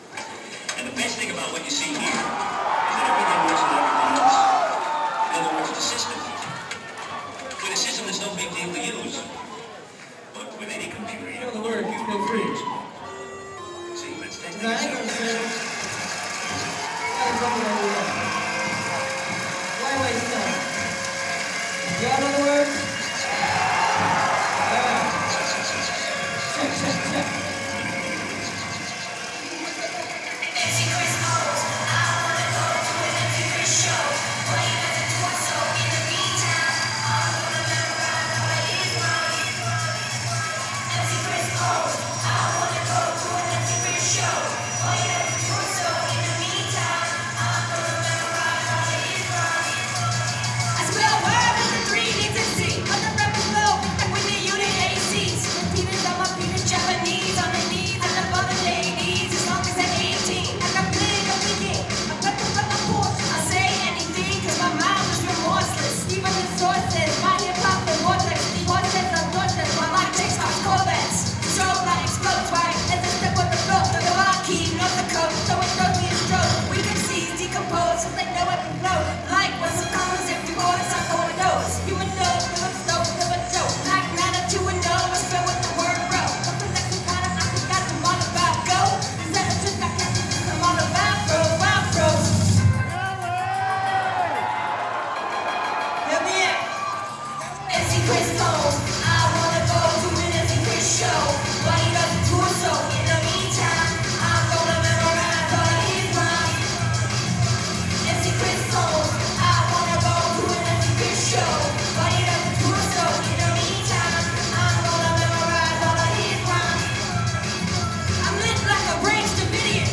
toledo, oh at club bijou on march 5th 2005
audience microphone recording